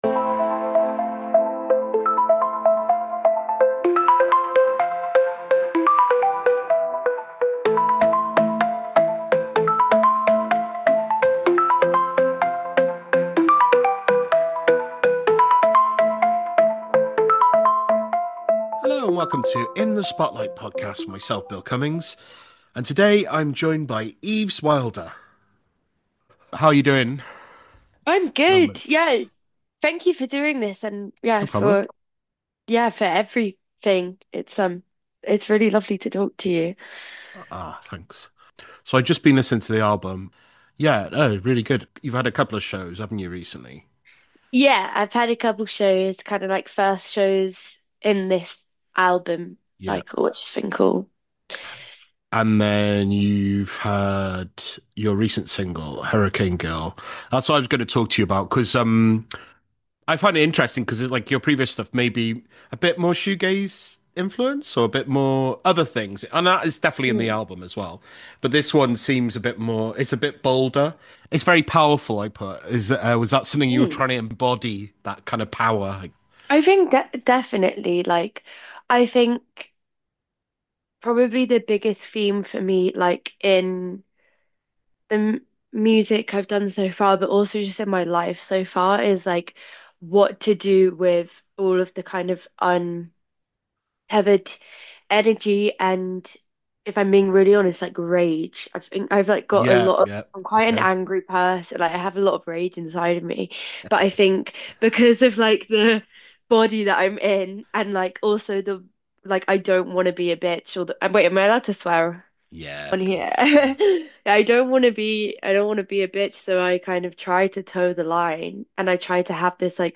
In The Spotlight: Interviews